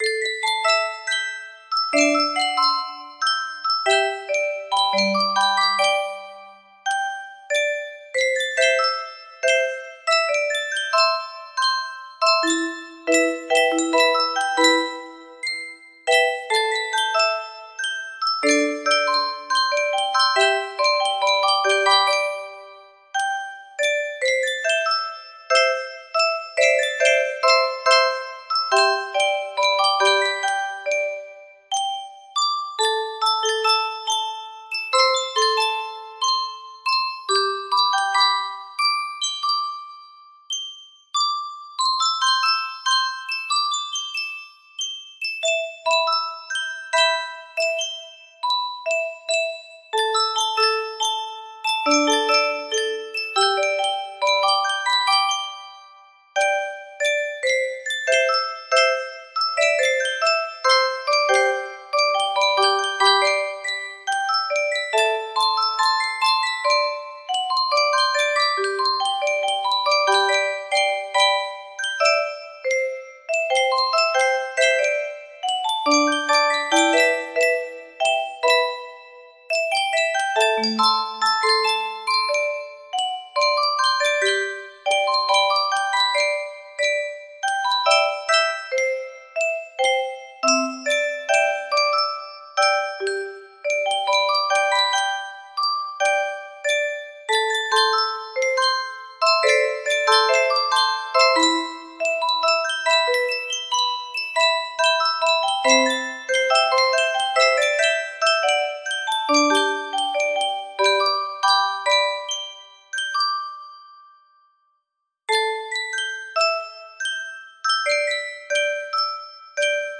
Mystic Melody 2 music box melody
Full range 60
Done, Proper tempo, No Reds.